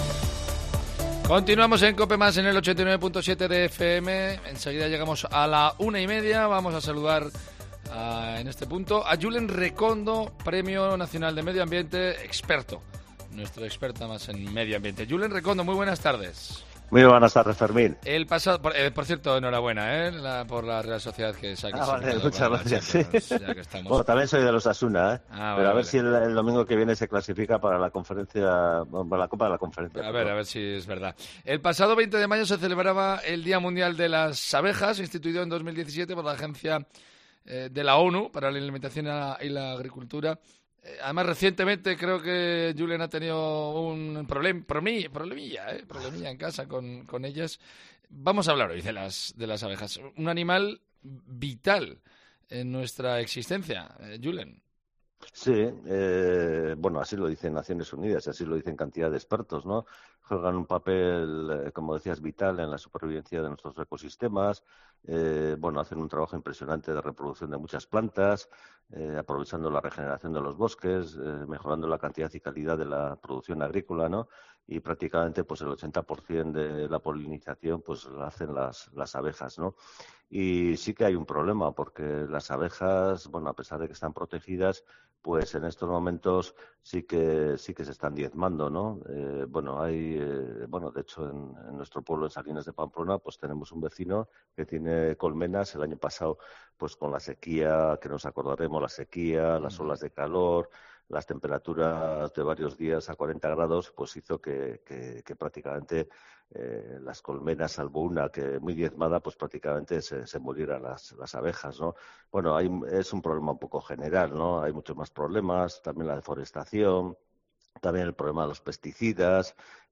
Y es lo que ha pasado en nuestra casa con dos enjambres de abejas, bastantes grandes, que es lo cuento en la entrevista de hoy.